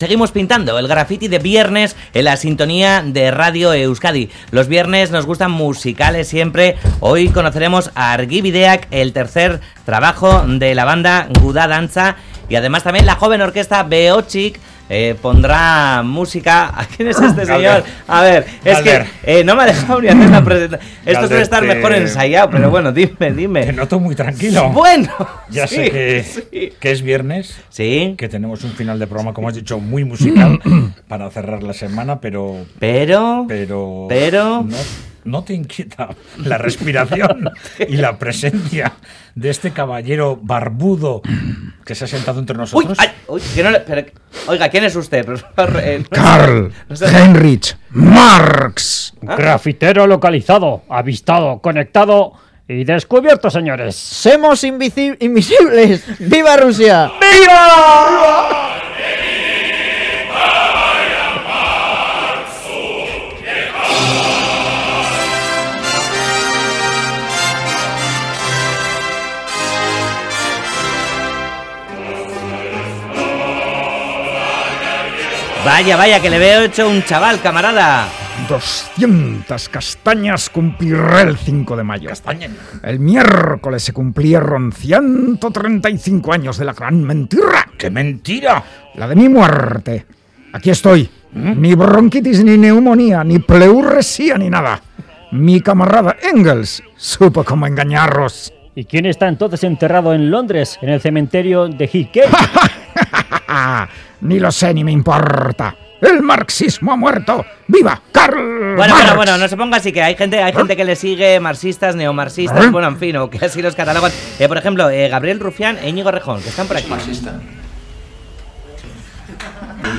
Radio Euskadi GRAFFITI Karl Marx quiere ir al Caribe Última actualización: 16/03/2018 18:28 (UTC+1) El graffitero de esta semana se descubre participando, en directo, en Graffiti para expresar sus ansias de viajar con el premio del concurso de este programa Whatsapp Whatsapp twitt telegram Enviar Copiar enlace nahieran